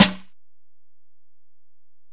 Snare (34).wav